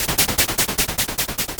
RI_RhythNoise_150-01.wav